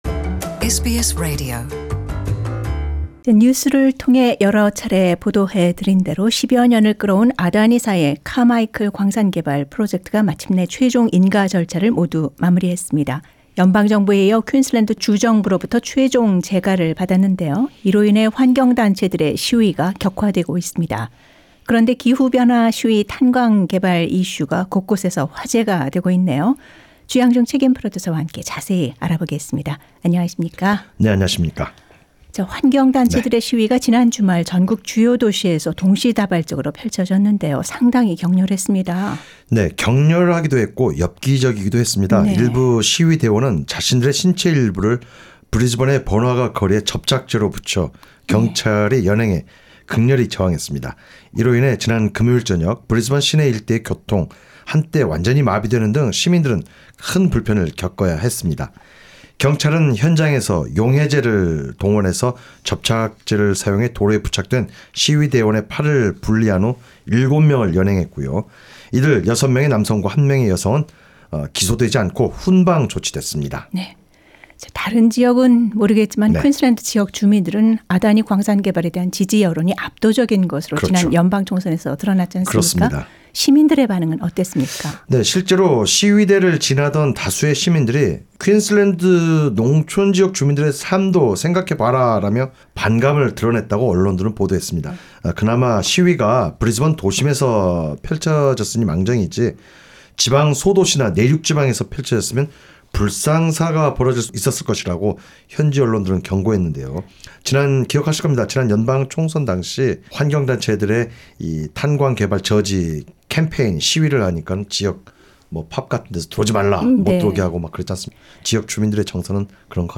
진행자: 뉴스를 통해 여러차례 보도해드린대로 10 여년을 끌어온 아 다 니 사의 카마이클 광산개발 프로젝트가 마침내 최종 인가 절차를 모두 마무리했습니다.